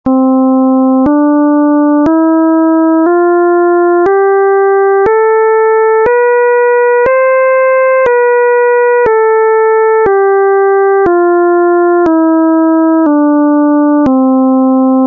Οἱ ἤχοι ἔχουν παραχθεῖ μὲ ὑπολογιστὴ μὲ ὑπέρθεση ἀρμονικῶν.]